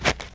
snow-04.ogg